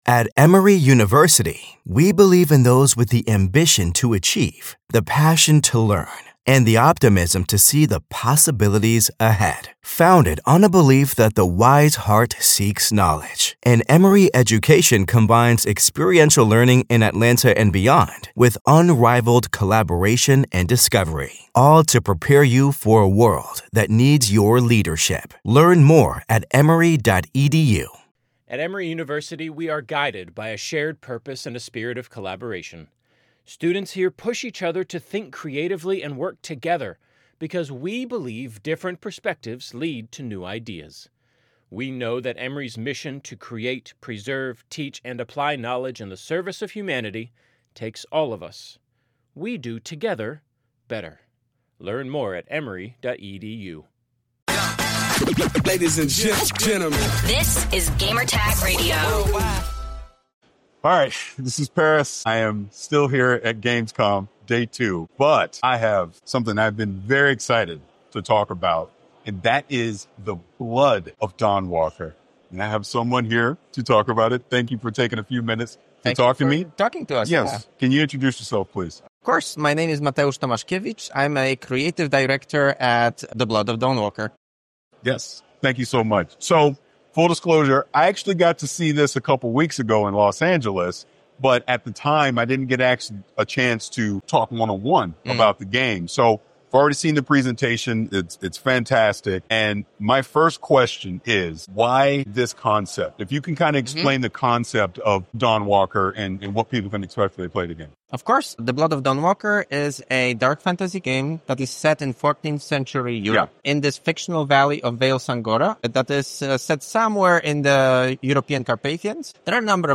The Blood of Dawnwalker Interview